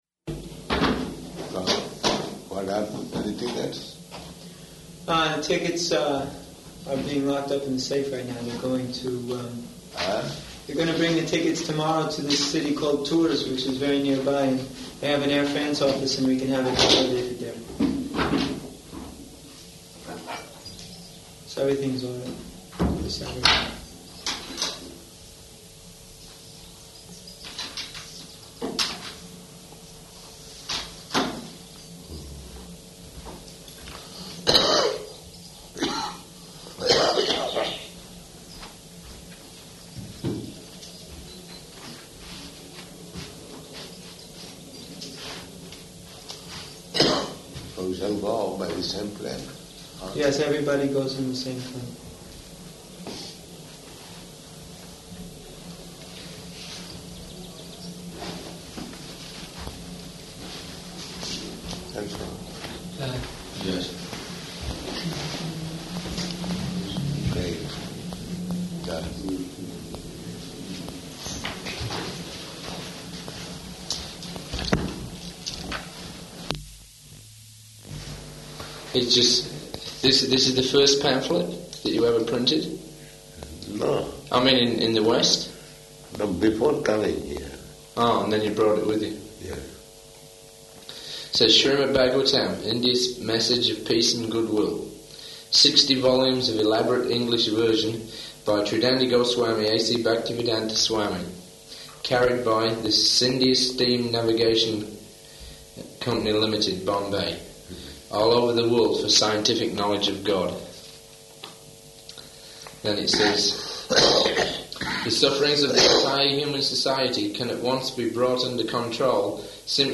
Room Conversation
Room Conversation --:-- --:-- Type: Conversation Dated: August 4th 1976 Location: New Māyāpur Audio file: 760804R1.NMR.mp3 Prabhupāda: So what happened to the tickets?